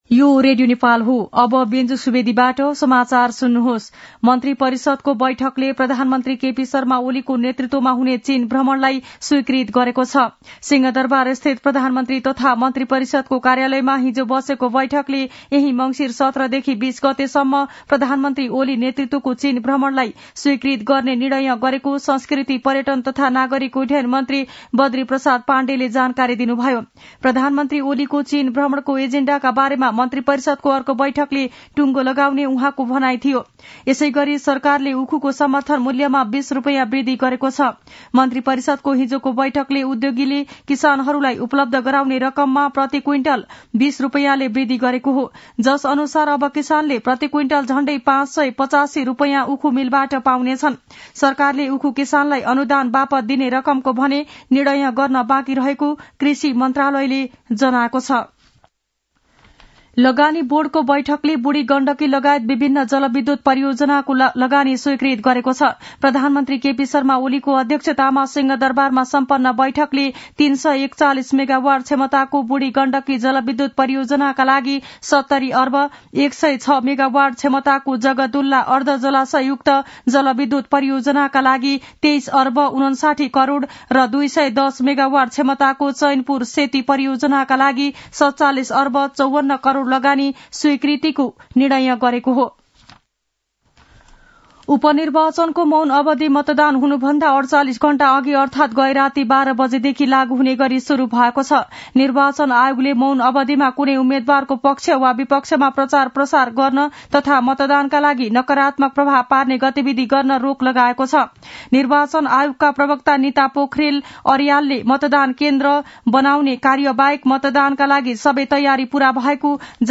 मध्यान्ह १२ बजेको नेपाली समाचार : १५ मंसिर , २०८१
12-am-nepali-news-1-11.mp3